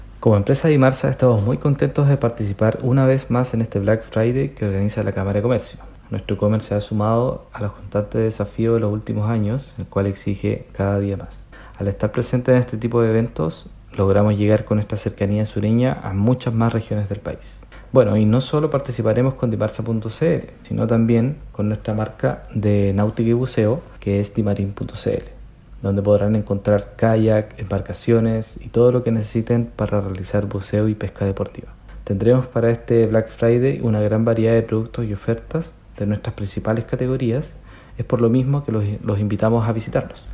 CUÑA-BLACK-FRIDAY-2.mp3